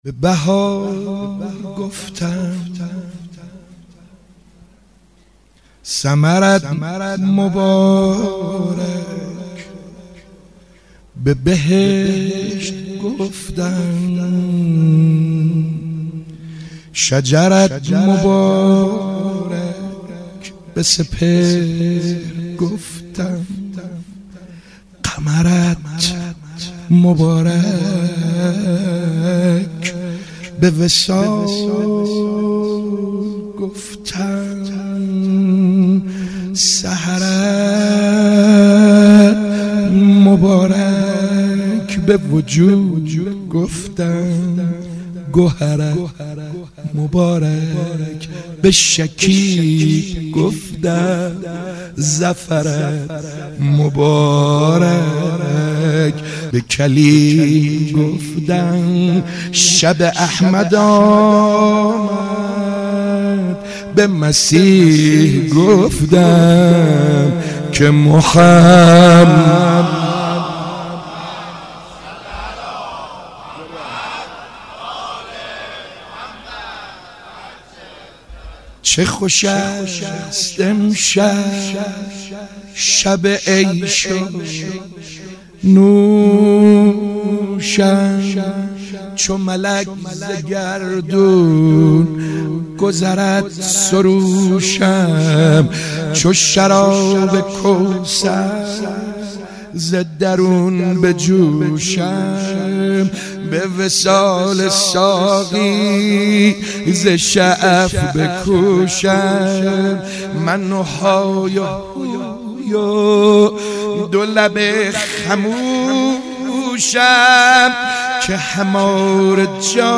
میلاد رسول اکرم(ص) و امام صادق(ع)/هیئت رزمندگان غرب تهران